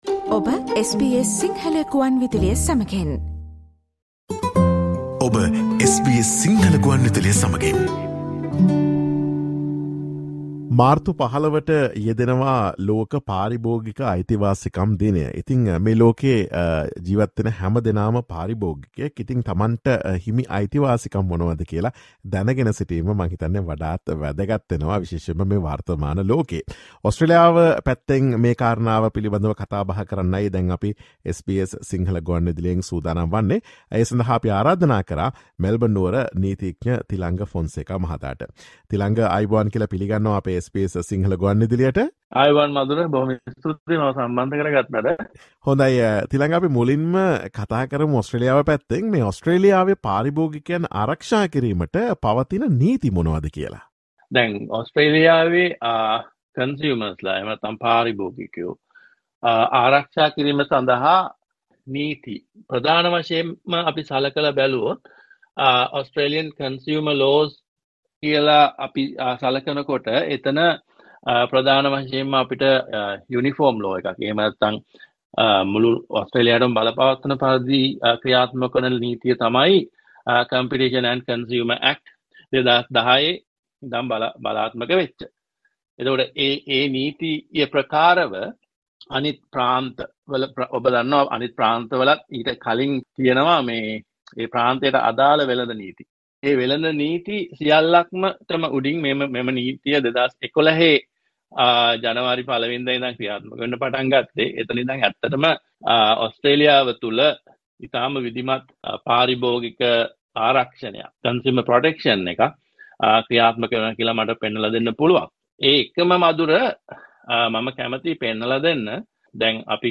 Listen to SBS Sinhala Radio's discussion on consumer rights in Australia focussing on World Consumer Rights Day on March 15.